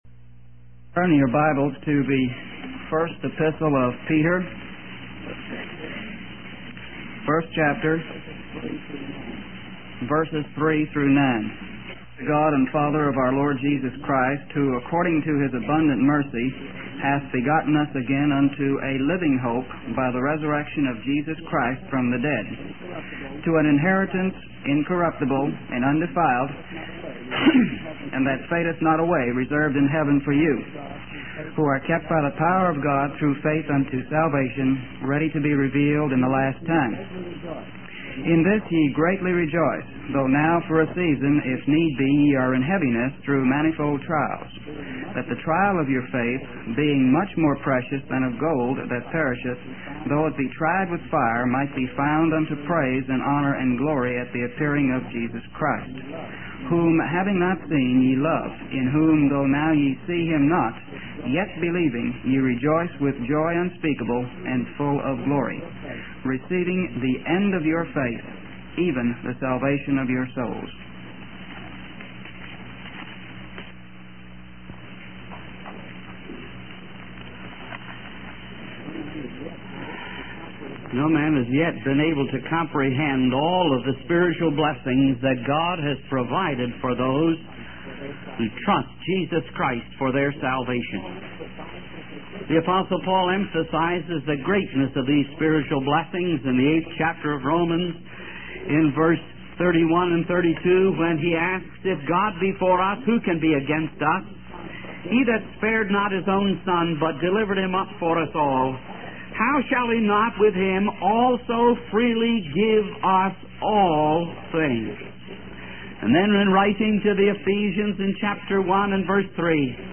In this sermon, the speaker emphasizes the importance of having faith in God's plan, even when we cannot see how things will work out. He encourages believers to respond to trials and suffering with faith and joy, knowing that God is using these experiences to conform us to the image of Jesus Christ. The speaker also highlights the preciousness of our faith, comparing it to gold that is tested by fire.